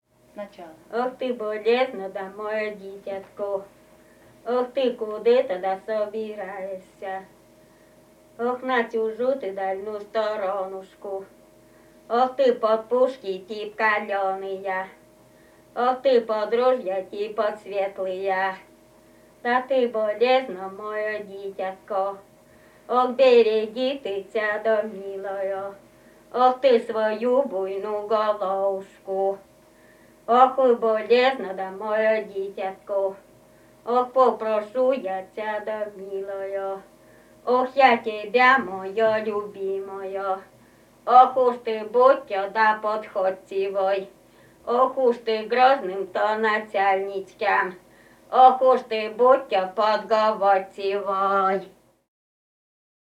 Музыка рекрутского обряда Костромской области
01 Рекрутское причитание «Ох